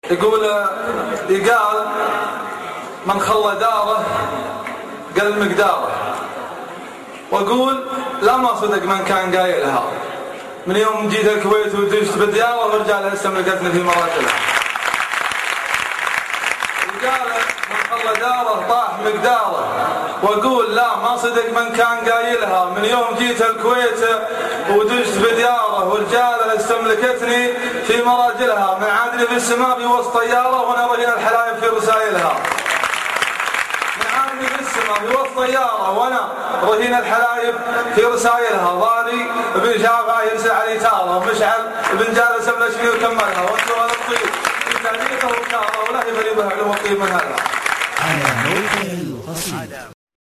يقال من خله داره ( اصبوحة جامعة الكويت )   05 ابريل 2012